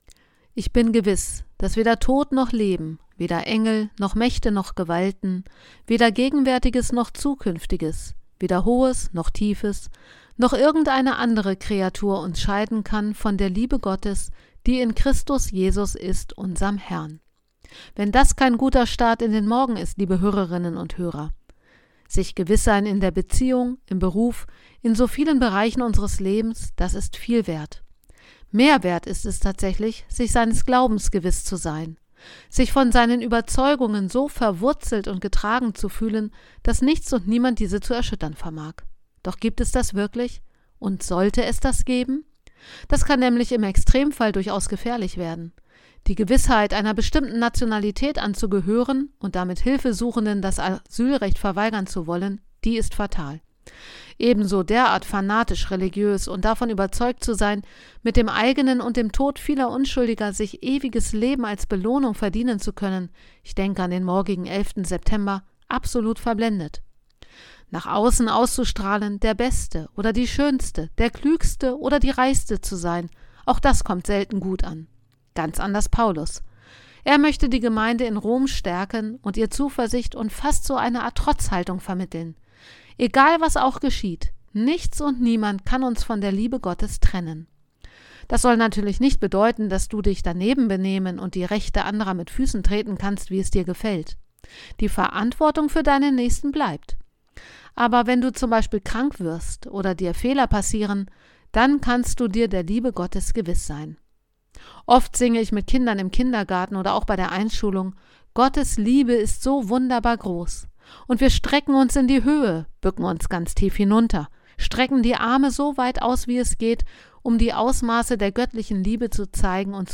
Radioandacht vom 10. September